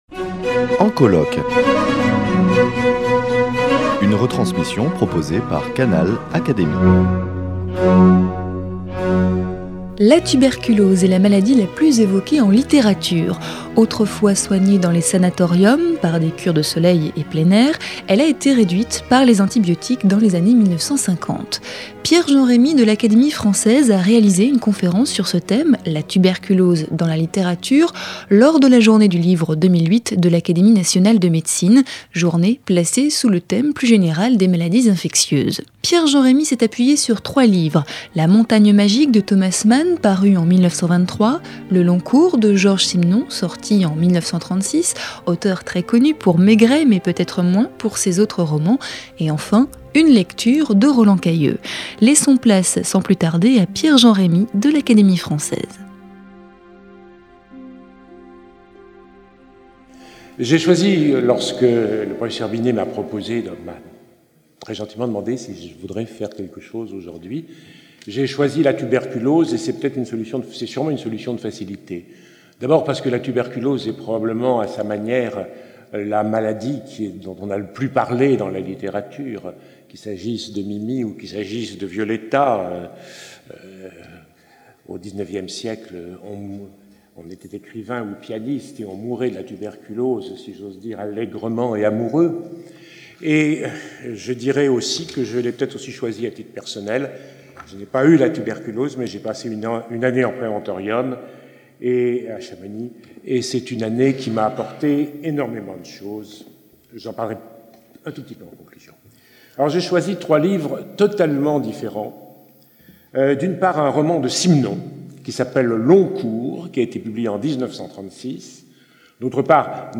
Georges Simenon, Thomas Mann et Roland Cailleux ont un point commun : avoir fait de la tuberculose le personnage principal de plusieurs de leurs romans. Lecture détaillée par l’académicien Pierre-Jean Rémy, dans le cadre de la journée du livre de l’Académie nationale de médecine en septembre 2008.